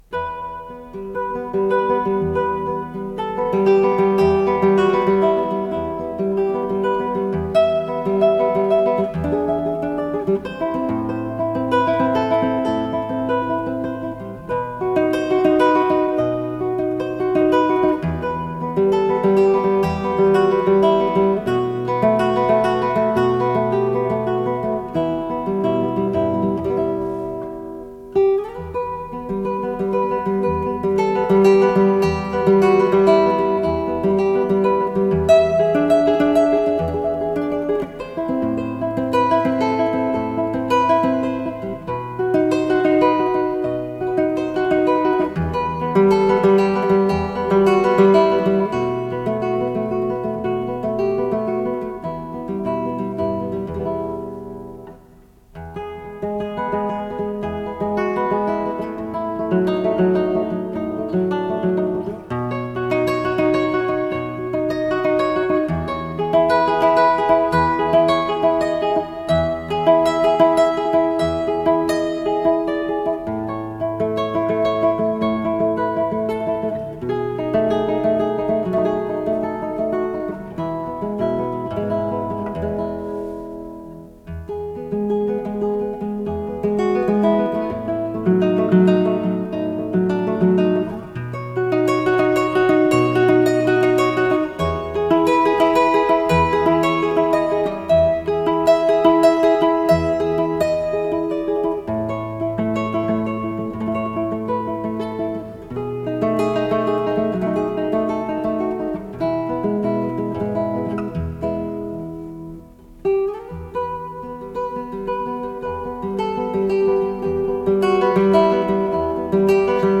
с профессиональной магнитной ленты
шестиструнная гитара
ВариантДубль моно